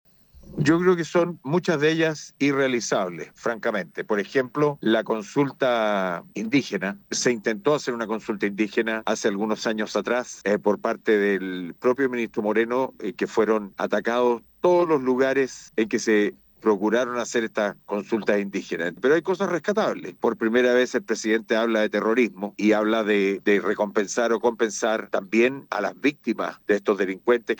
El diputado de la misma colectividad, Miguel Becker, sostuvo que “hay cosas irrealizables y rescatables“.